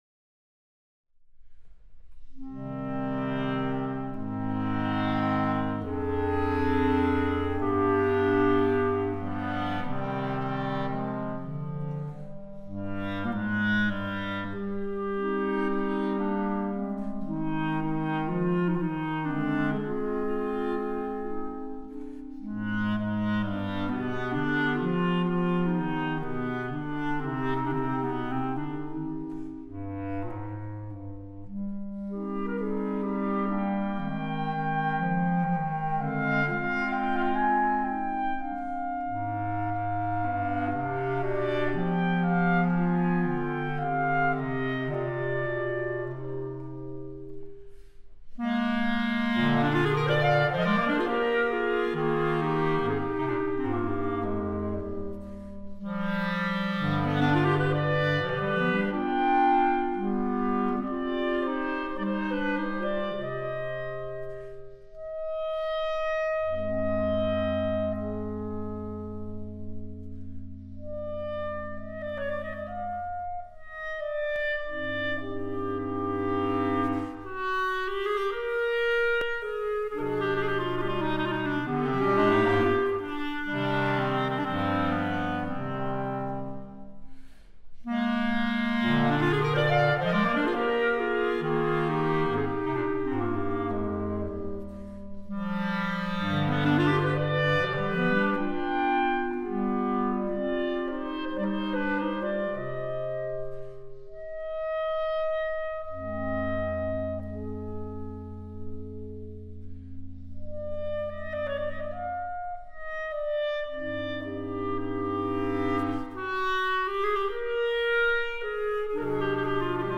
Gattung: für Klarinettenquartett
Besetzung: Instrumentalnoten für Klarinette